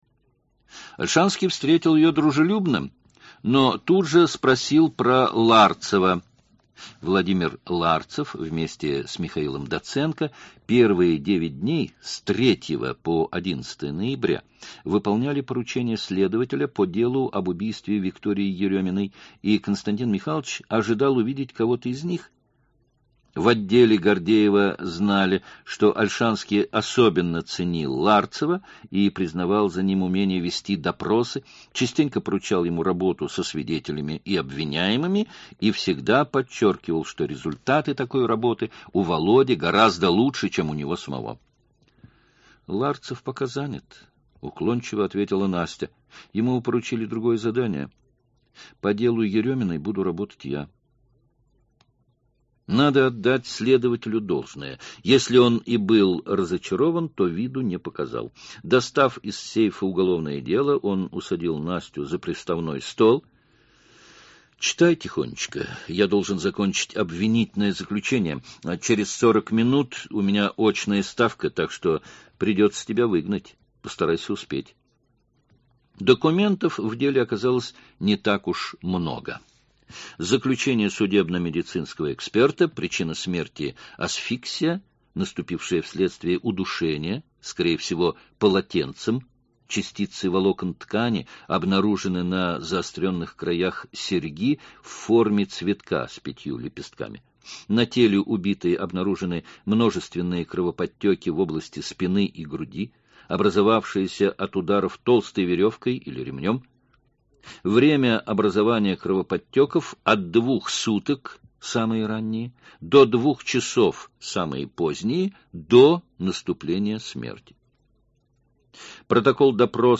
Aудиокнига Украденный сон